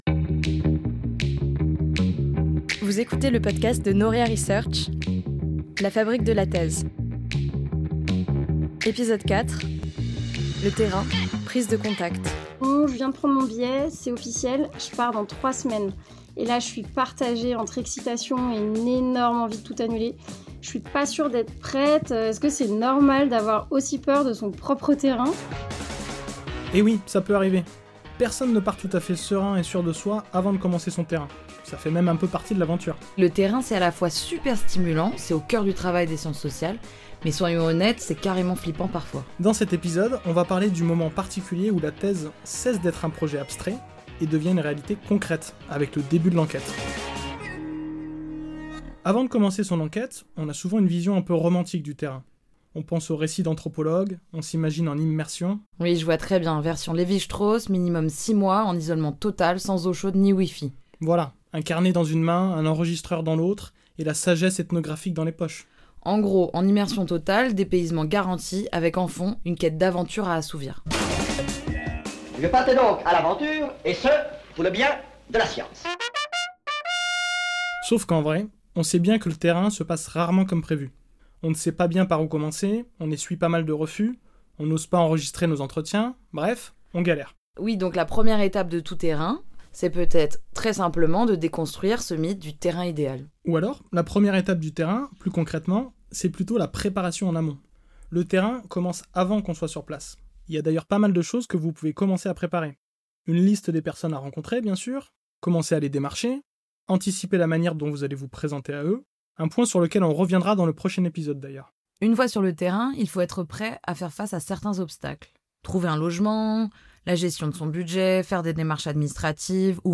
Entretien
Les témoignages de doctorant·es montrent comment garder le cap, accepter ses limites, ajuster sa démarche, prendre soin de soi et cultiver une vraie réflexivité: une plongée honnête dans ce moment fondateur de la thèse.